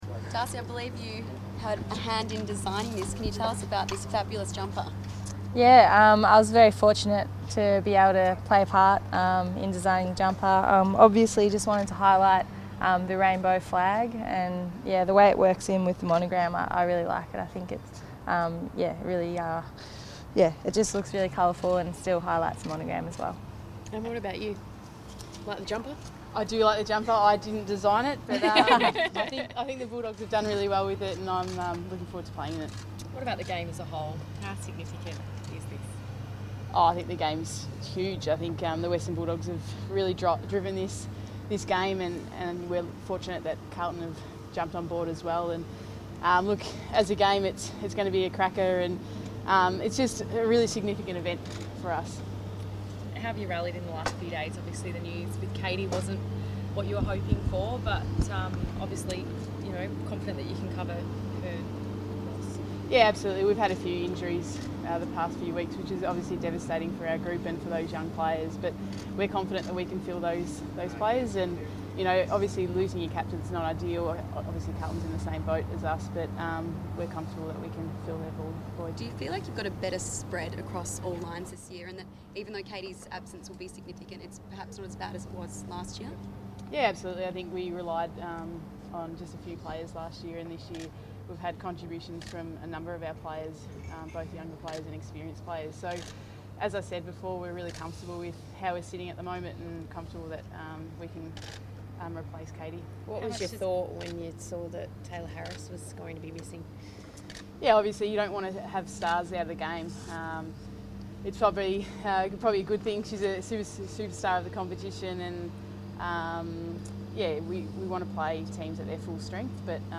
Darcy Vescio press conference | February 20
Darcy Vescio and Western Bulldogs player Emma Kearney front the media at Whitten Oval ahead of Friday night's inaugural AFLW Pride Game.